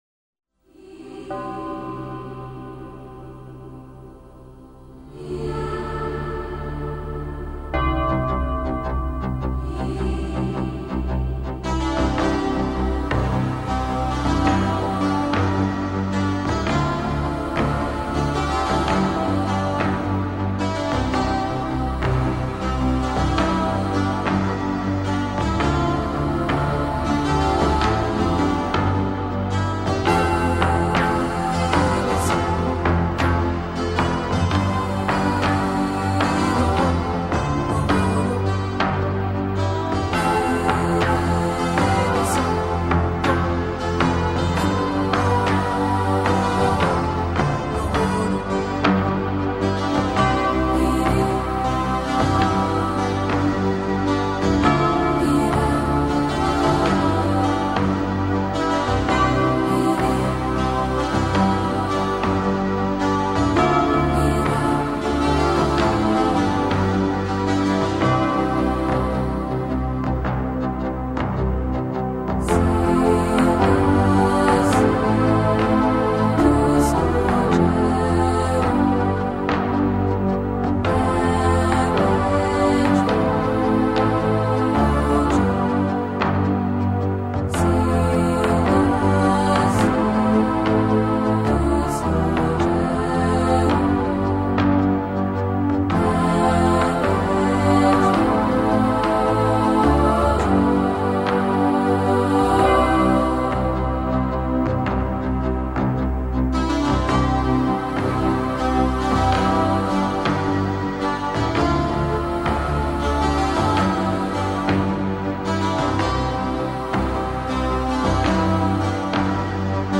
幽静的民族情怀
以民谣的旋律为主，电子合成器的意境营造为辅,衬以古典音乐的思维